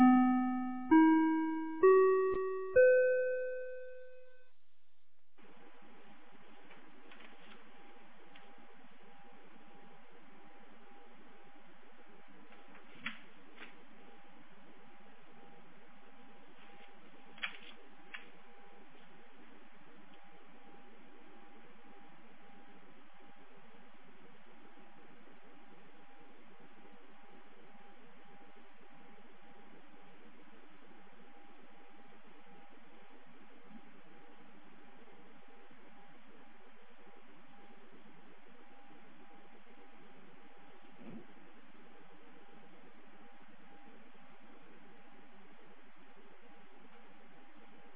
2019年08月15日 07時03分に、由良町から全地区へ放送がありました。
放送音声